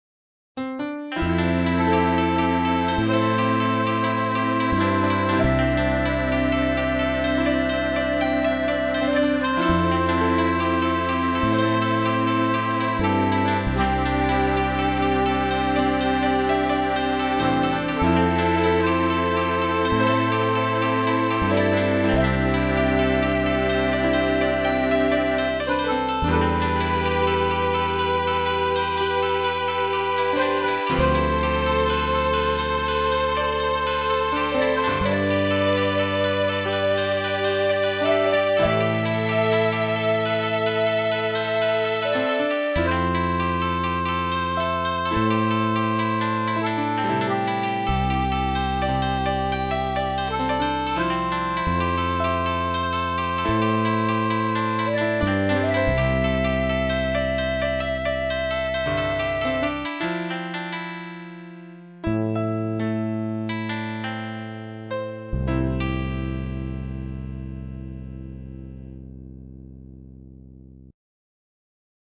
Appeggois were used in this tune for the Koto the timing is not as tight as I would like it.